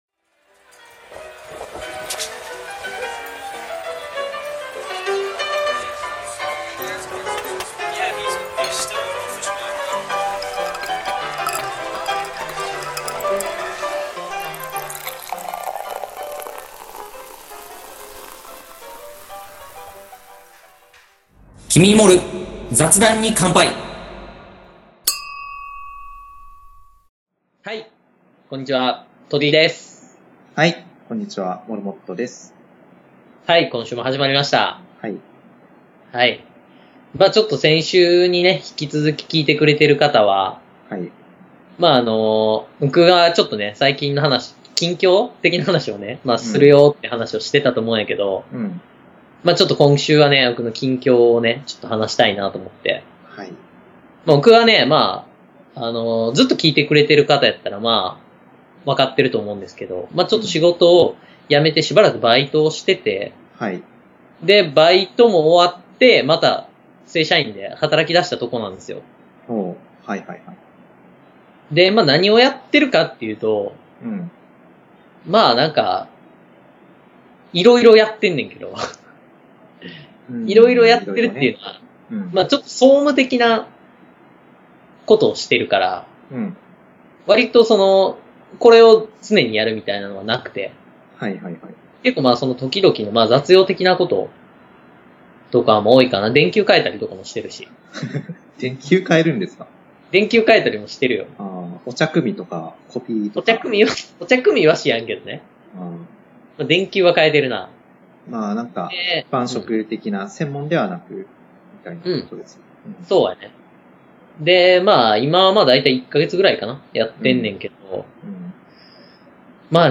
収録PCの電池切れにより話半ばで終わります///